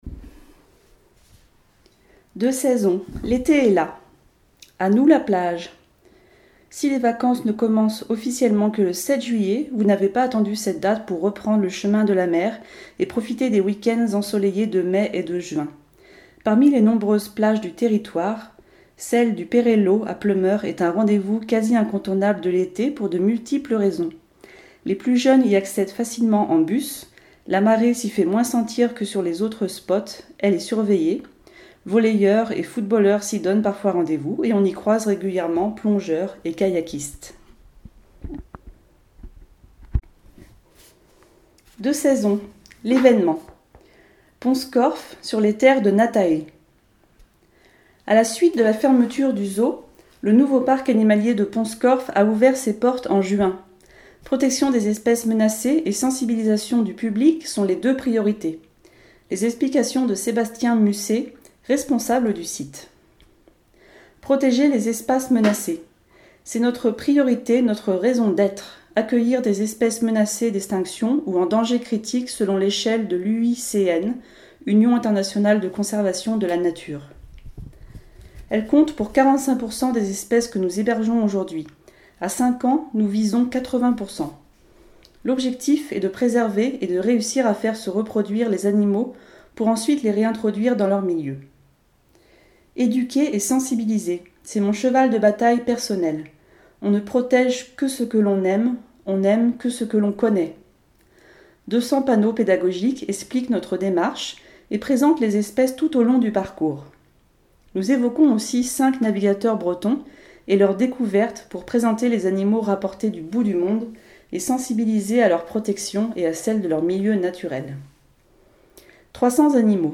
Version audio du magazine